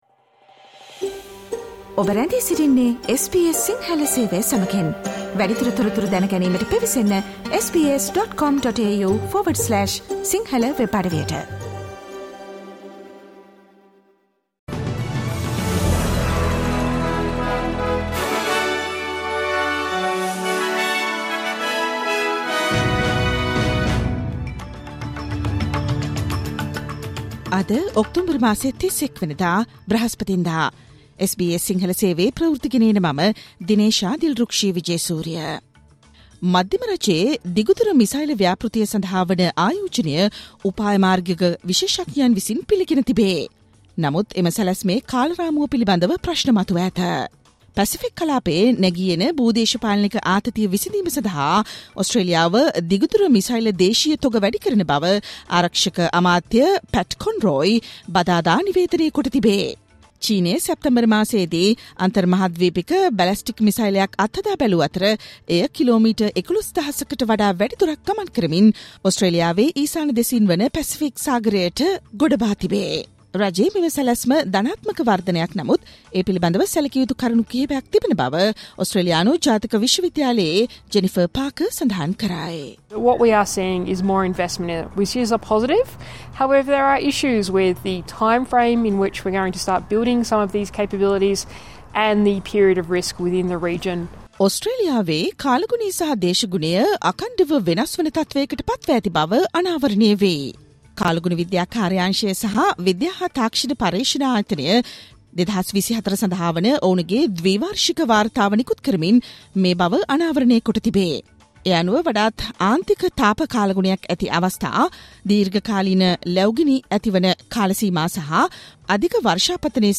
Australian news in Sinhala, foreign news, and sports news in brief.